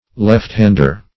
lefthander \left`hand"er\, left-hander \left`-hand"er\n.